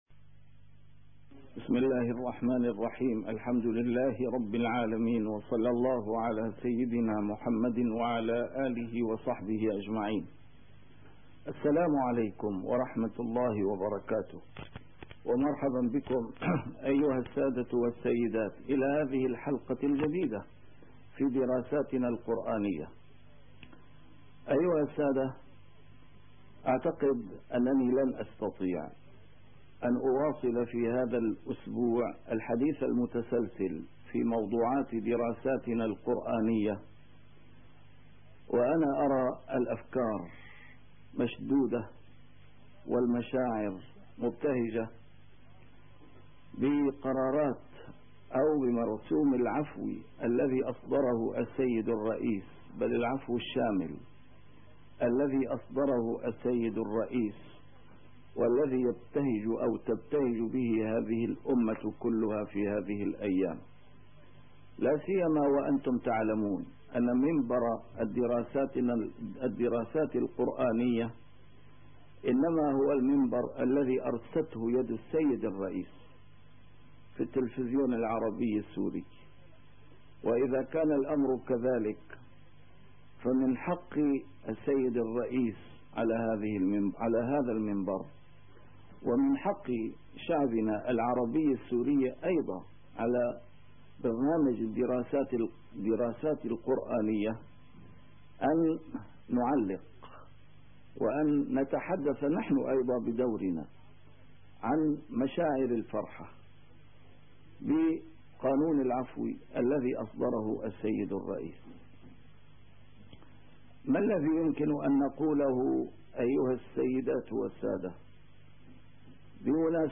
A MARTYR SCHOLAR: IMAM MUHAMMAD SAEED RAMADAN AL-BOUTI - الدروس العلمية - دراسات قرآنية - حلقة خاصة عن العفو الشامل الذي أصدره السيد الرئيس حافظ الأسد + مكانة المرآة في كتاب الله عز وجل